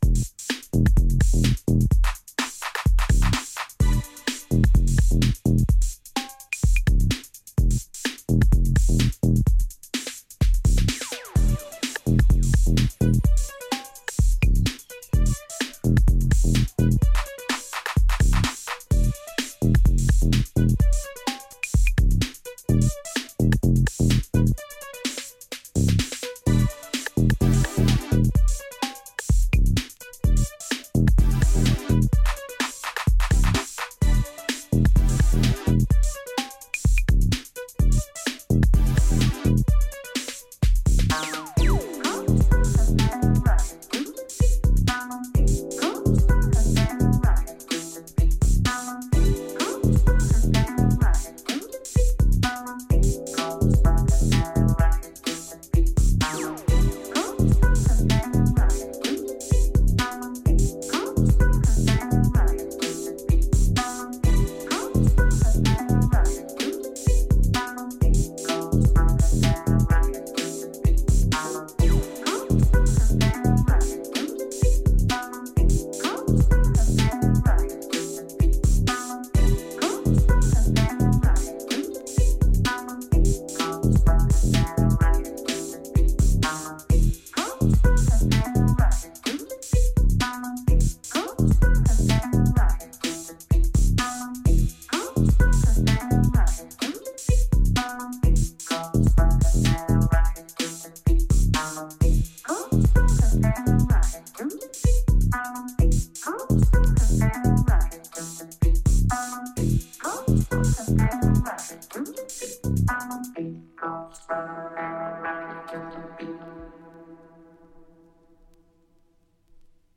ジャンル(スタイル) HOUSE / NU DISCO